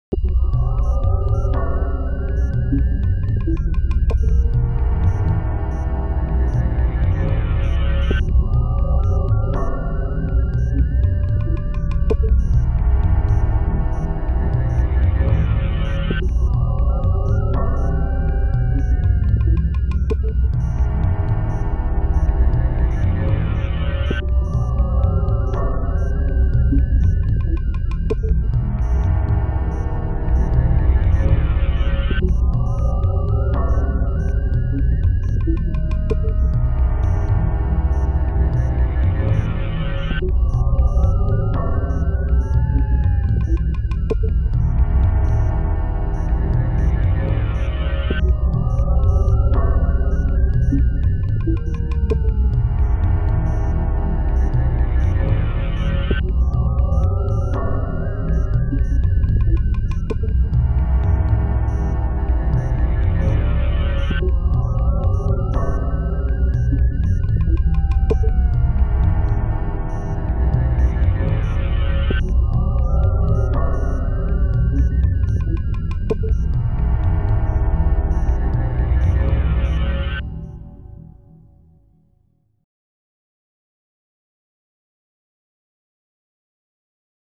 horror